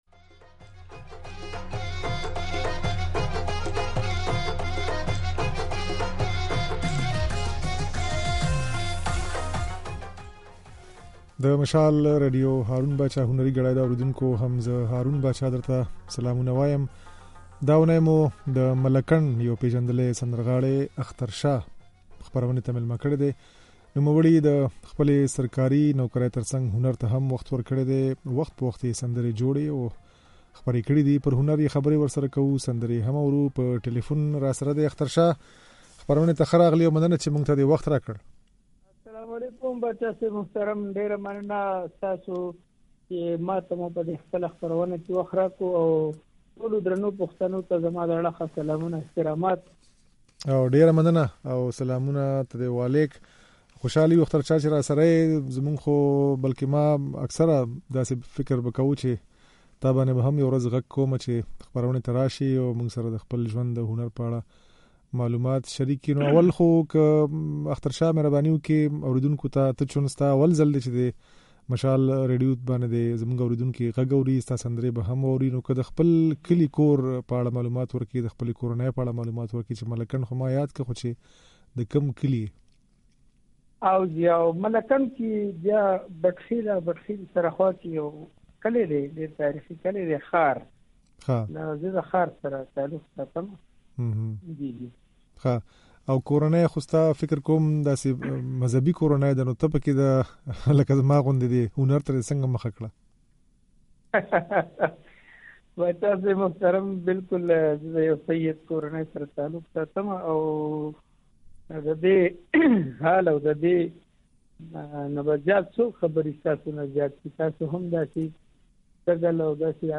د نوموړي د ژوند او هنر په اړه خبرې او ځينې سندرې يې د غږ په ځای کې اورېدای شئ.